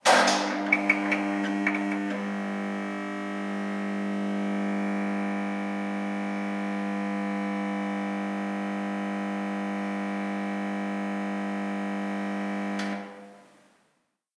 Título Luces fluorescentes Formateatu: audio/mpeg Fitxategiaren tamaina 224.51 KB Iraupena: 14 seconds: Me gusta Descriptores fluorescente luz Energía y su transformación Electricidad y electrónica. Sonidos: Industria Sonidos: Hogar